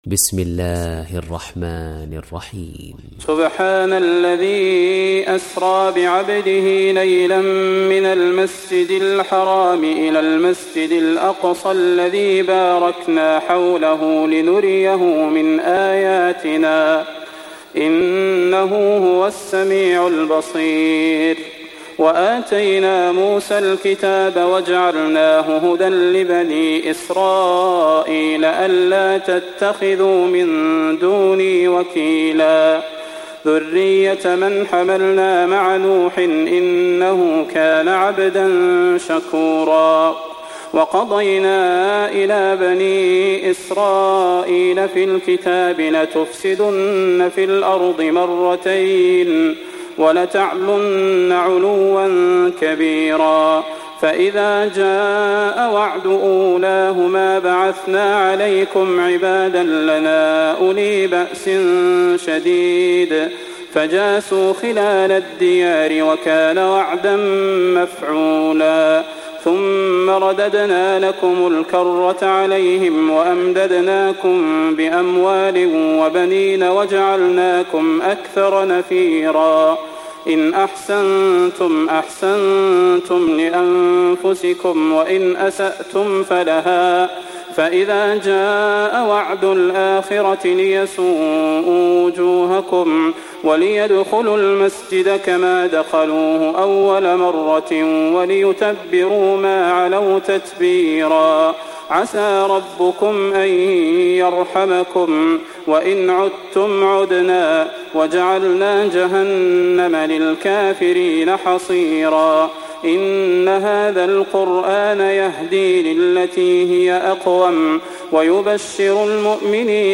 Hafs থেকে Asim